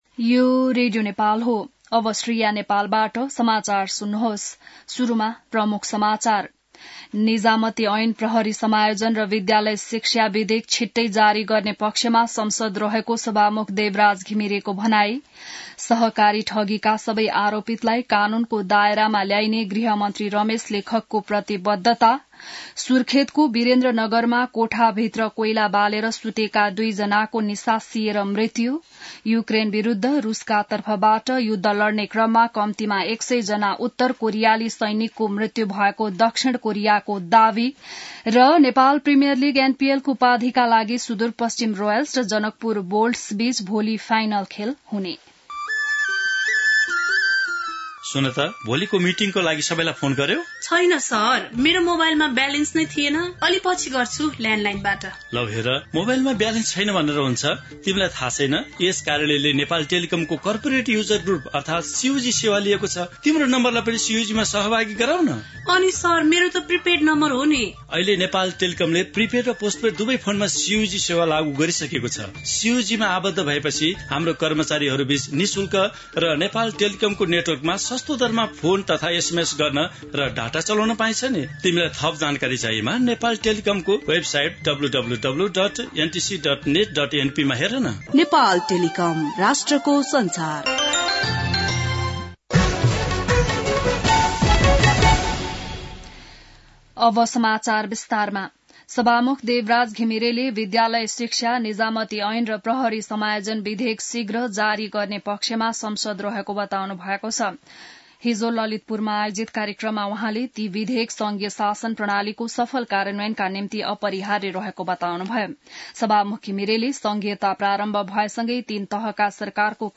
An online outlet of Nepal's national radio broadcaster
बिहान ७ बजेको नेपाली समाचार : ६ पुष , २०८१